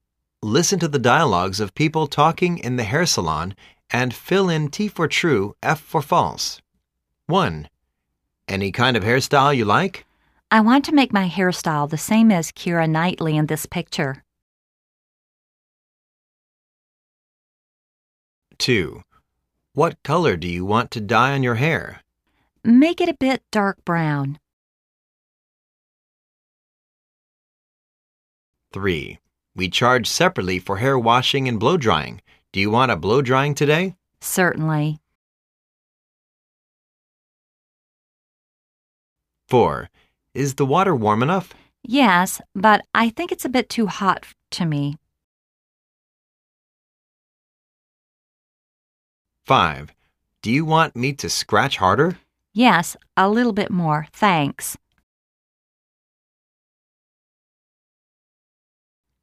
Listen to the dialogues of people talking in the hair saloon and fill in T for "True", F for "False".